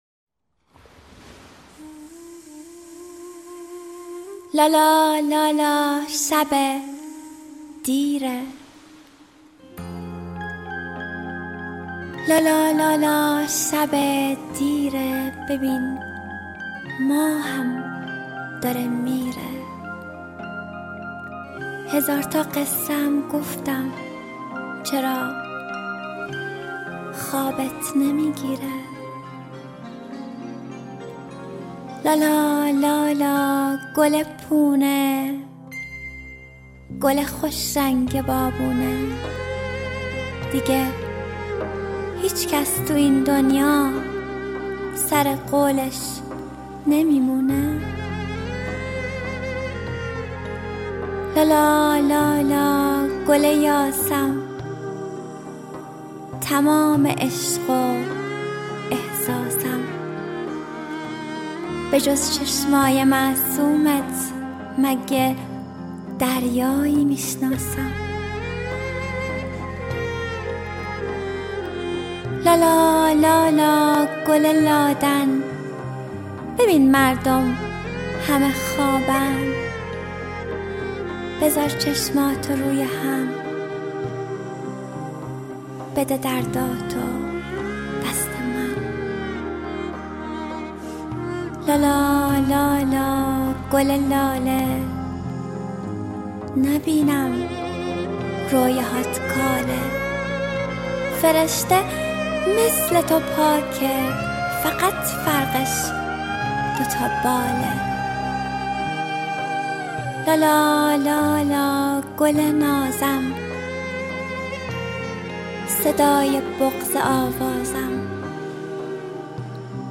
دانلود دکلمه لالایی با صدای مریم حیدرزاده